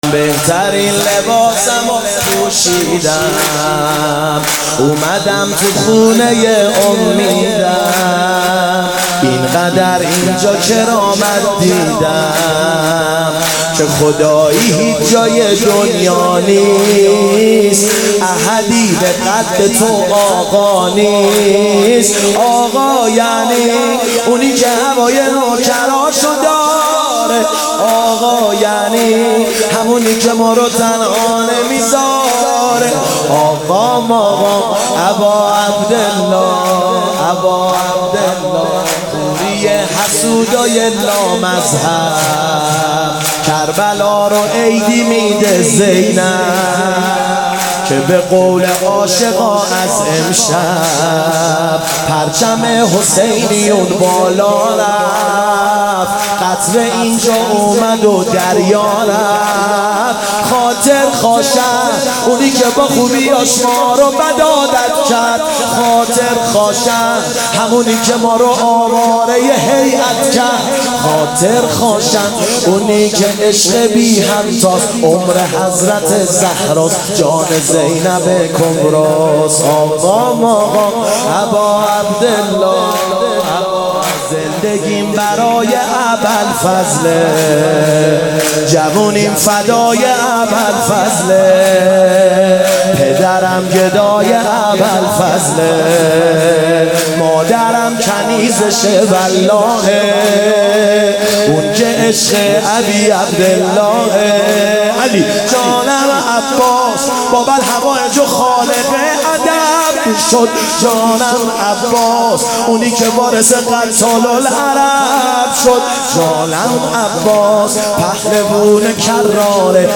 میلاد حضرت عباس (ع) 1399 | هیئت ابوالفضل قزآن